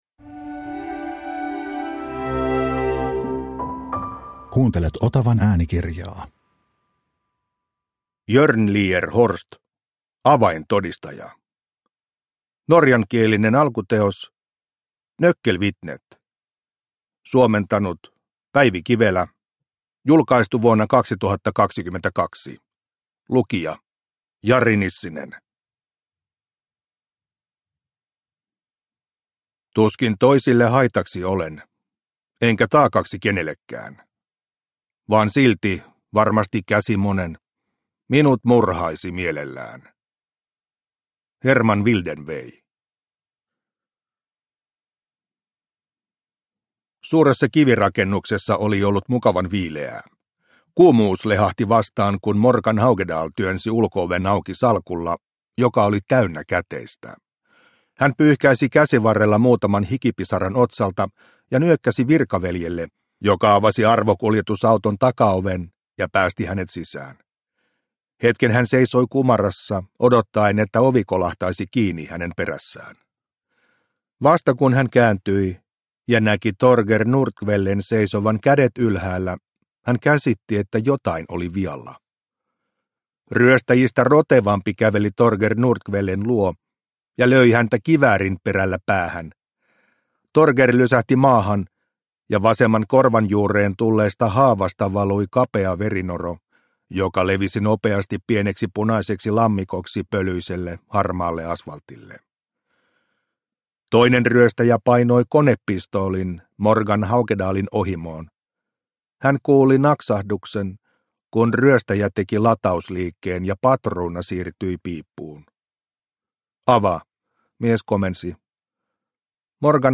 Avaintodistaja – Ljudbok – Laddas ner